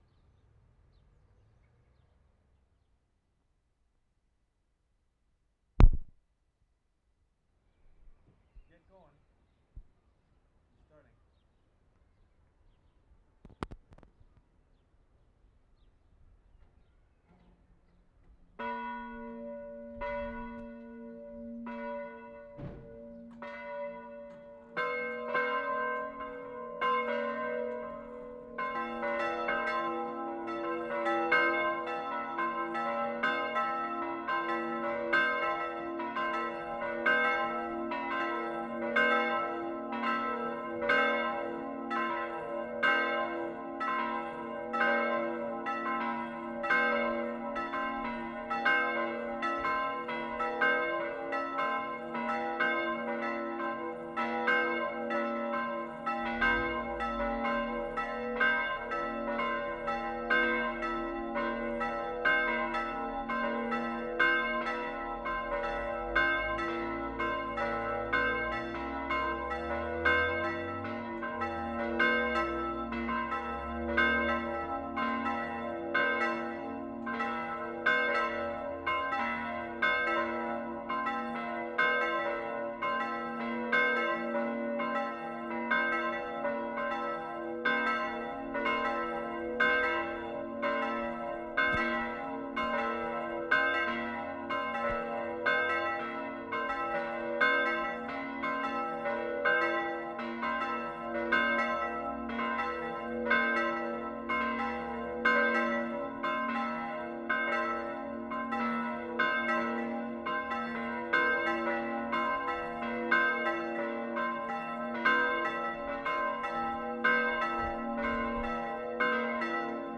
WORLD SOUNDSCAPE PROJECT TAPE LIBRARY
Lesconil, France April 17/75
CATHOLIC CHURCH BELLS, Angelus.
3. One lower clock bell - - - - - - - - - then higher single bell (Angelus) ringing.